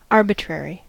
arbitrary: Wikimedia Commons US English Pronunciations
En-us-arbitrary.WAV